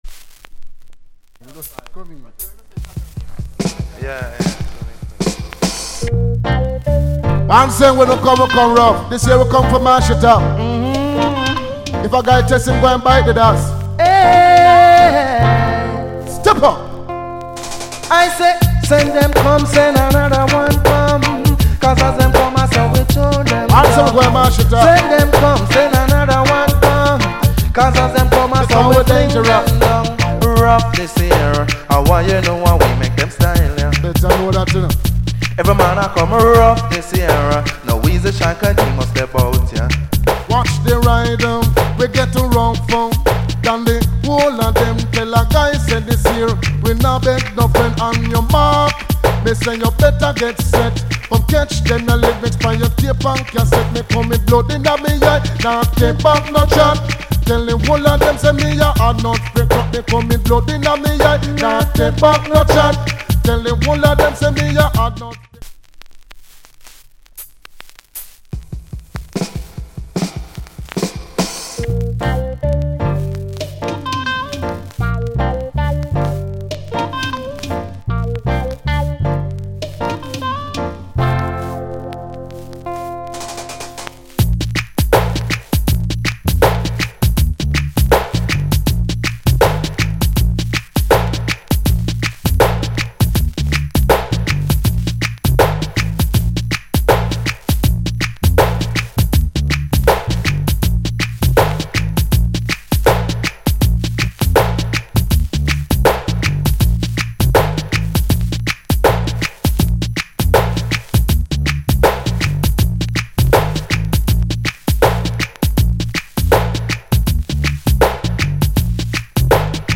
** 盤面うっすら曇っており少しノイジー。